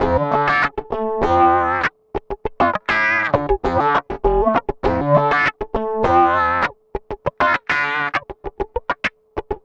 OOZIE WAH -R.wav